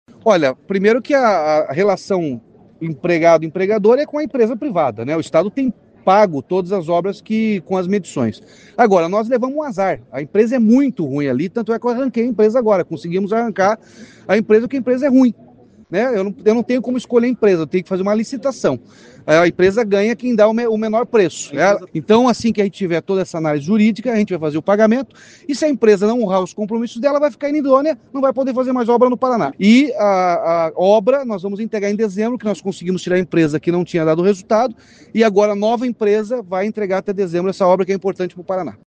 Em coletiva de imprensa, o governador Ratinho Júnior falou sobre a situação de cerca de 100 operários demitidos sem receber salários atrasados e encargos trabalhistas.